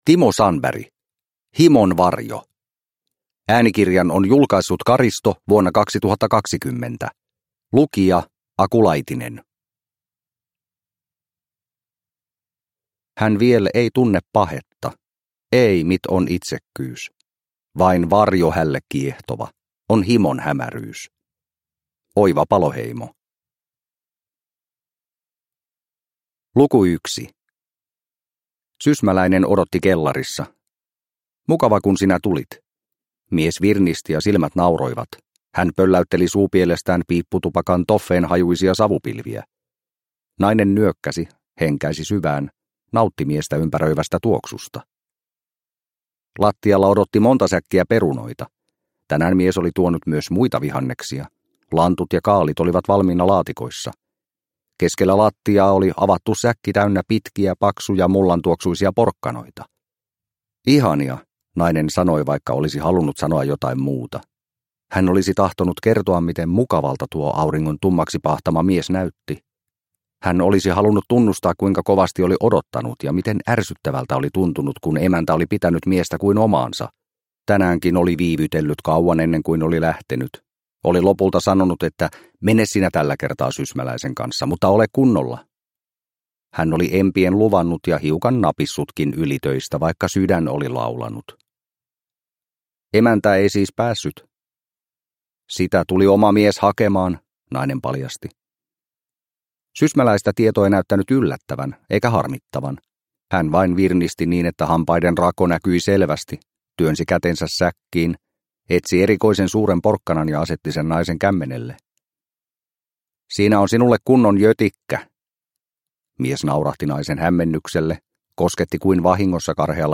Himon varjo – Ljudbok – Laddas ner